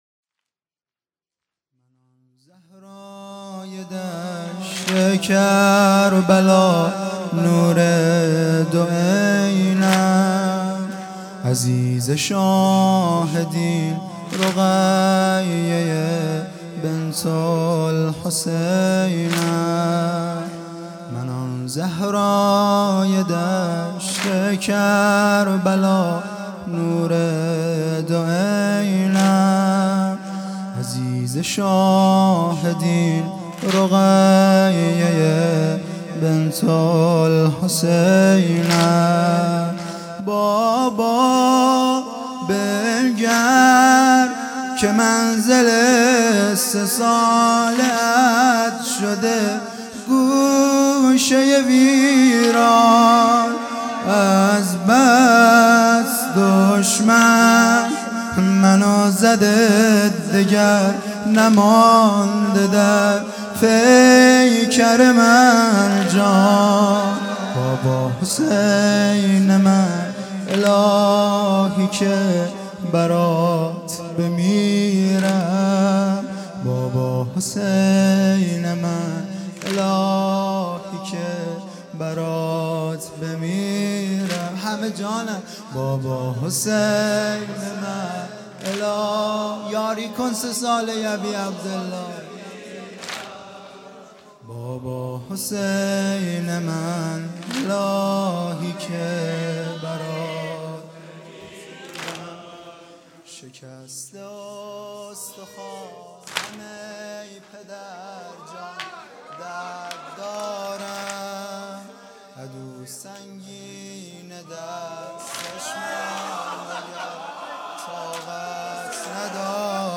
من آن زهرای دشت کربلا|شب سوم محرم ۹۵